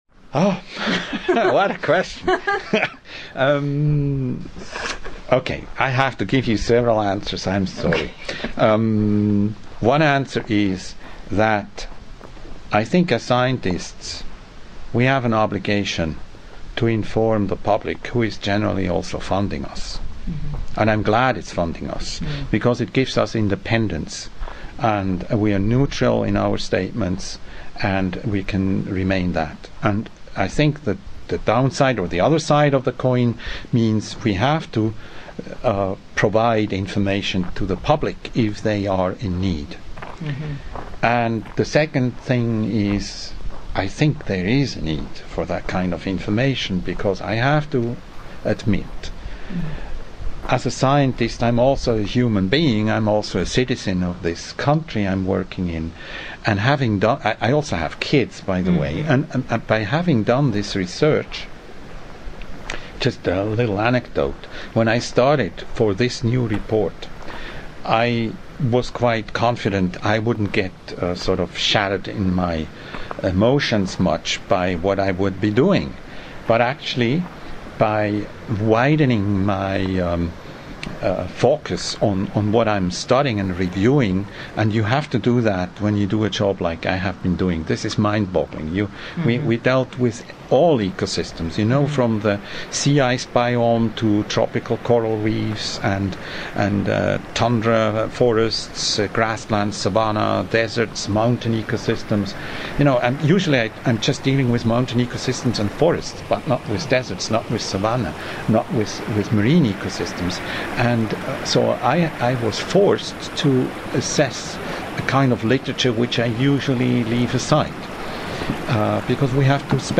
Soundscape Series